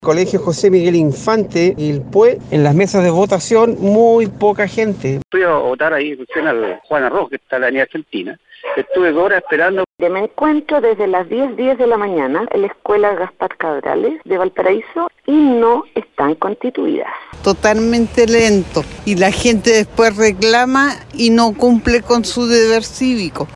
Así lo relataron los auditores a Radio Bío Bío, quienes se vieron afectados por la demora en las mesas.
cu-primarias-valparaiso-mix-votantes-.mp3